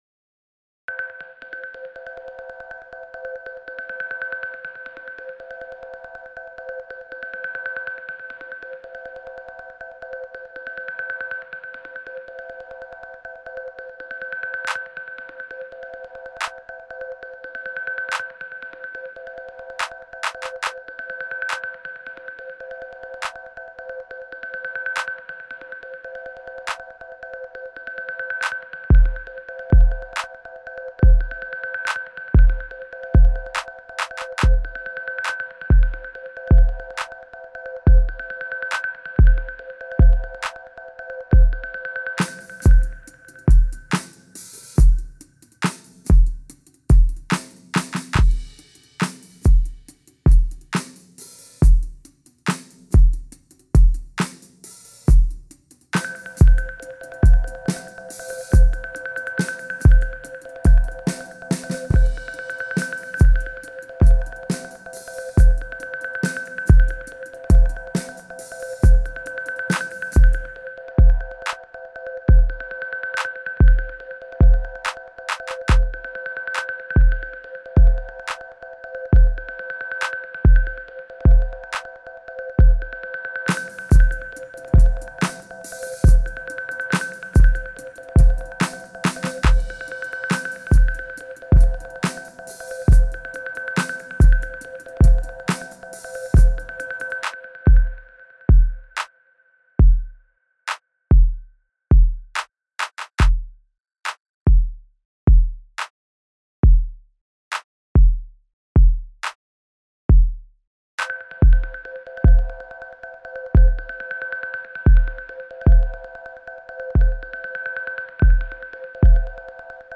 LoopBeats
crinsp_139-5BPM.mp3